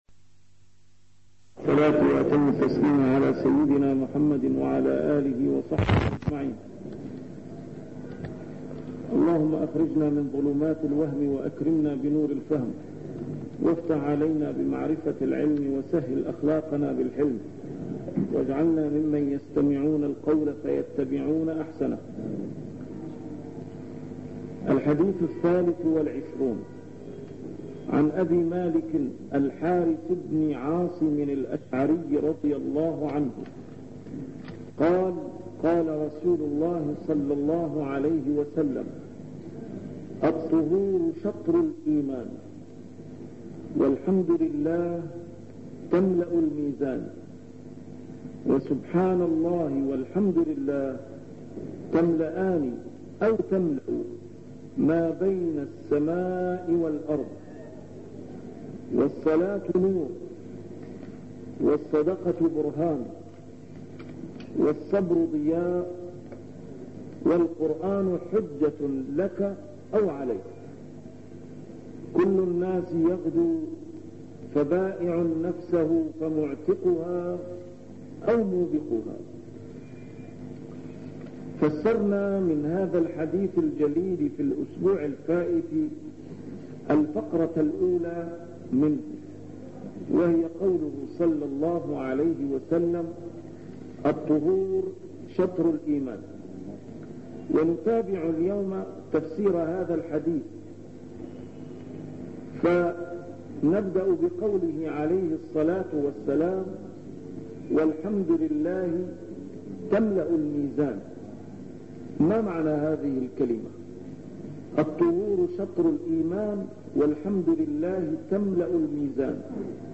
A MARTYR SCHOLAR: IMAM MUHAMMAD SAEED RAMADAN AL-BOUTI - الدروس العلمية - شرح الأحاديث الأربعين النووية - تتمة شرح الحديث الثالث والعشرون: حديث مالك بن الحارث (الطهور شطر الإيمان) 71